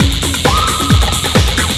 TECHNO125BPM 5.wav